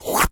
Animal_Impersonations
lizard_tongue_lick_02.wav